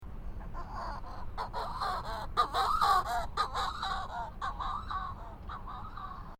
Manx Shearwater Project
Manx Shearwater Recordings, July 2007, Co. Kerry, Ireland
hoarse